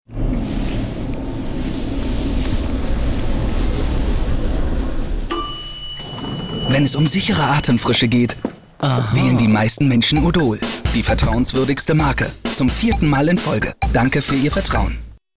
deutscher Sprecher, Dialekt: norddeutsches Platt
Sprechprobe: Sonstiges (Muttersprache):
german voice over artist